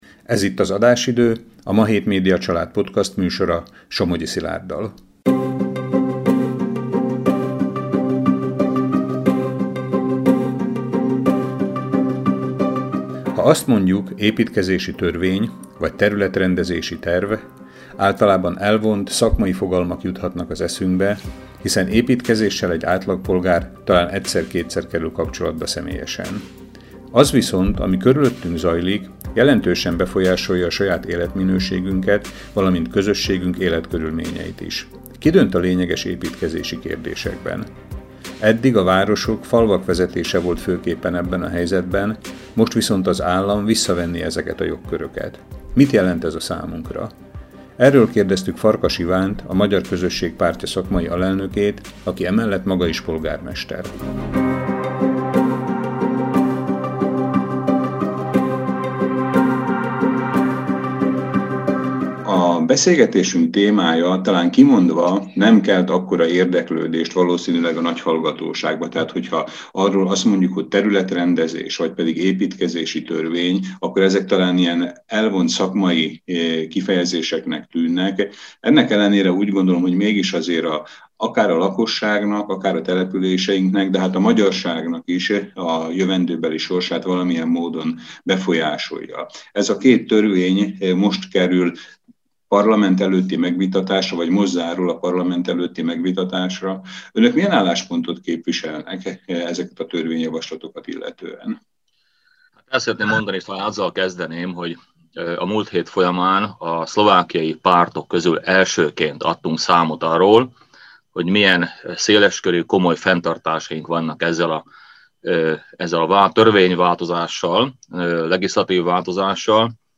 Farkas Iván, az MKP alelnöke, polgármester beszél e heti podcastunkban a készülő új építkezési törvényről, főképpen annak veszélyeiről.